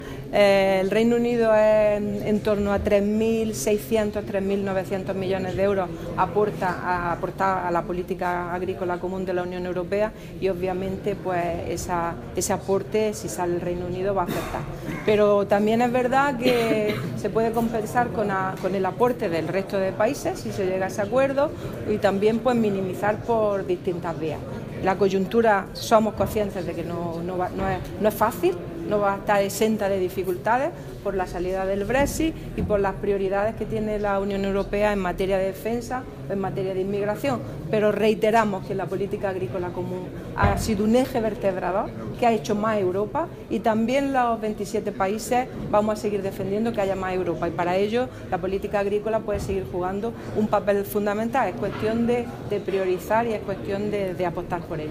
Declaraciones de Carmen Ortiz sobre ayudas para la incorporación de jóvenes al sector agrario